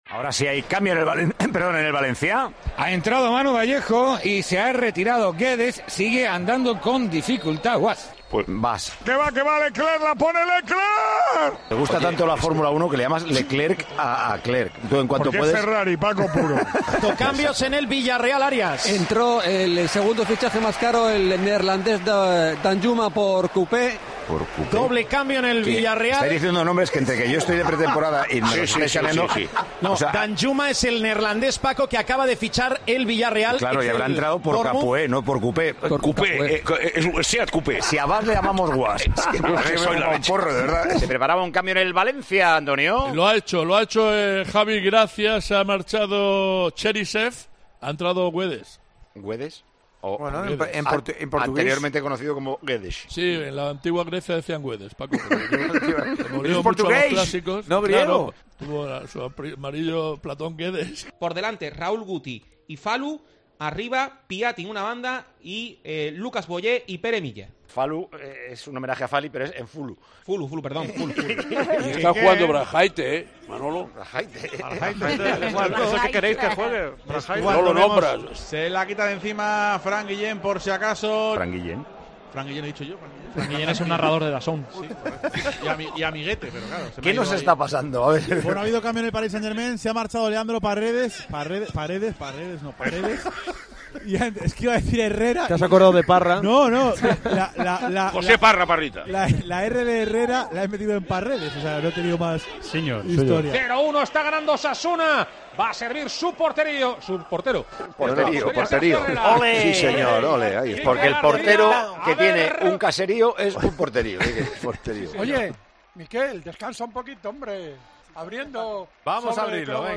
los gazapos y sonidos más locos que se han producido a lo largo de las cientos de horas de directo en Tiempo de Juego...